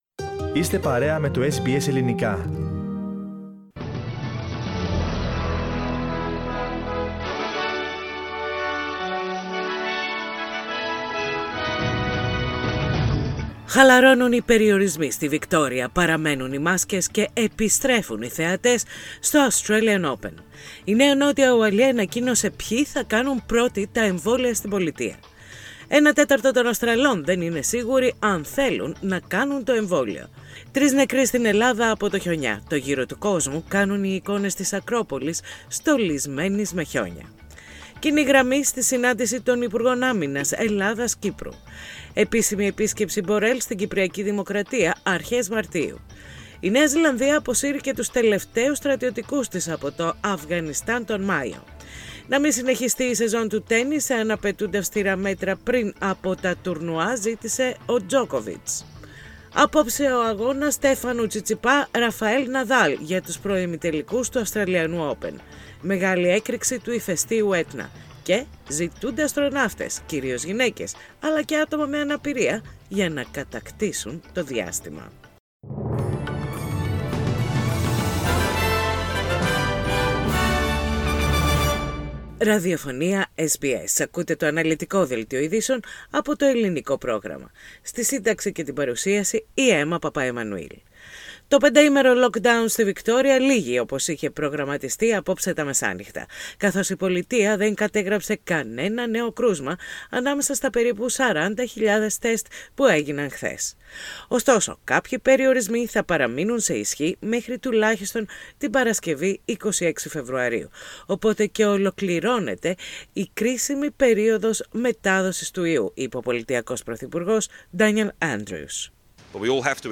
Δελτίο Ειδήσεων - Τετάρτη 17.2.21